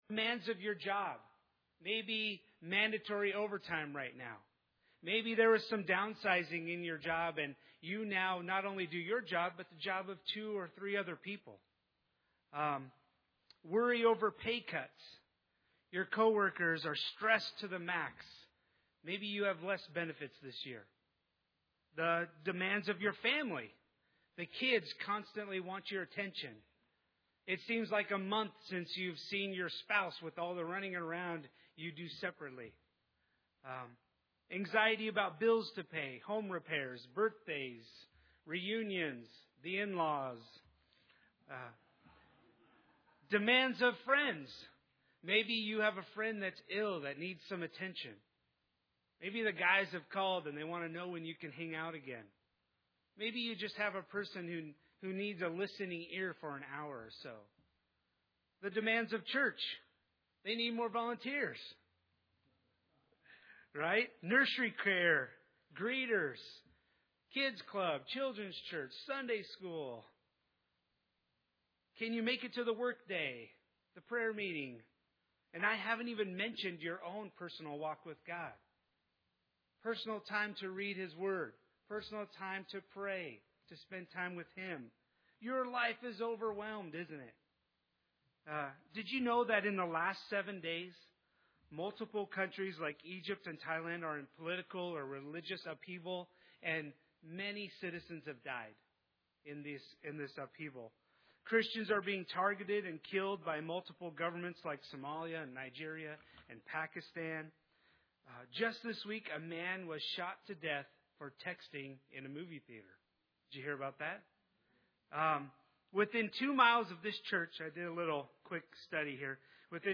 Passage: Philippians 4 Service Type: Sunday Service